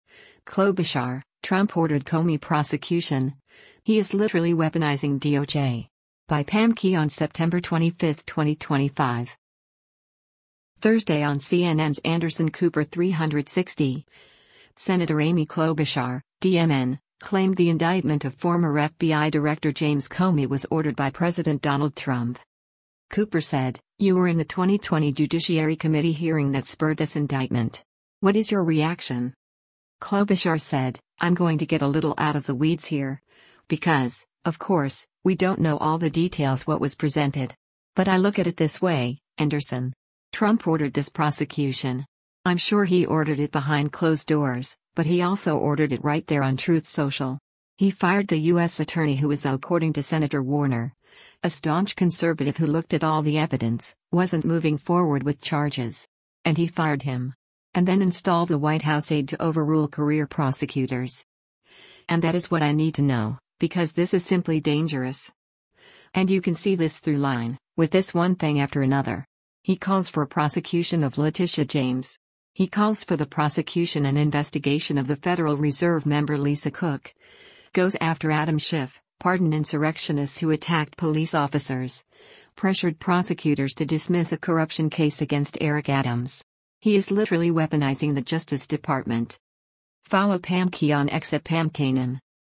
Thursday on CNN’s “Anderson Cooper 360,” Sen. Amy Klobuchar (D-MN) claimed the indictment of former FBI Director James Comey was “ordered” by President Donald Trump.